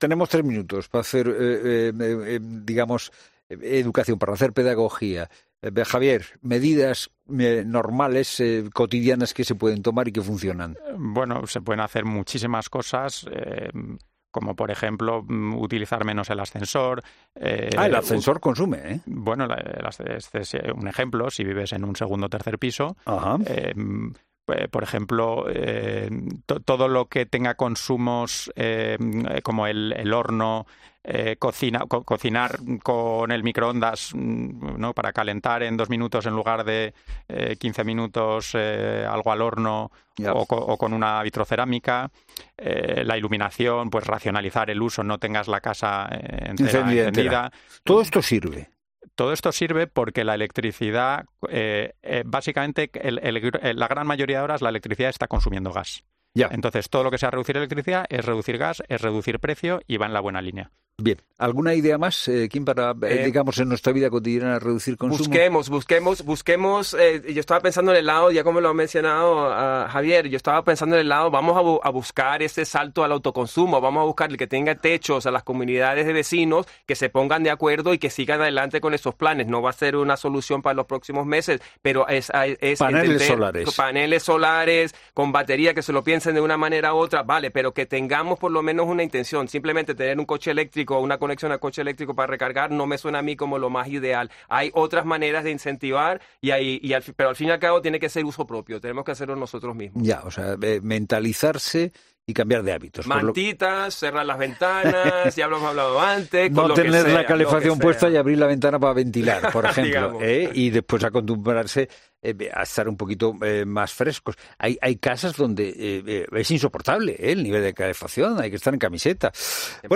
Por ello, en La Tarde de COPE dos expertos en el mercado energético han dado claves a los oyentes sobre qué pueden hacer para frenar la escalada en el precio de la factura.